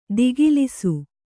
♪ digilisu